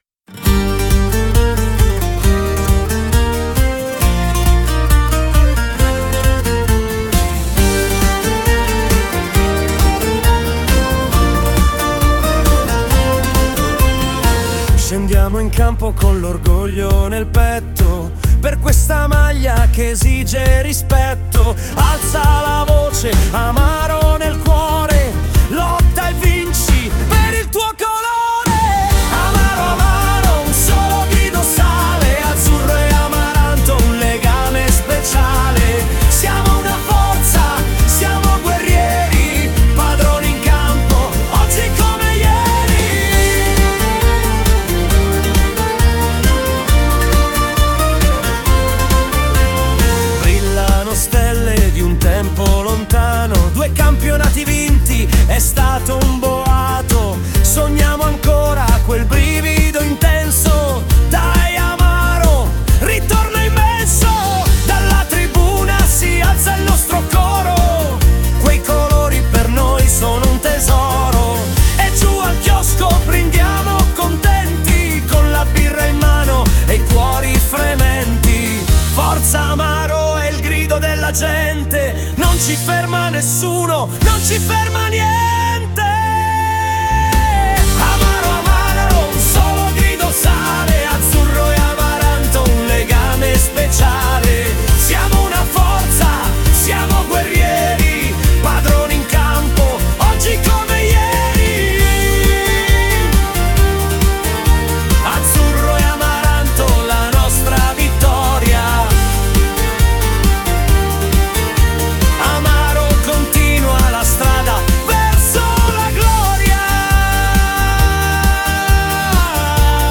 Ora l’Amaro ha anche un inno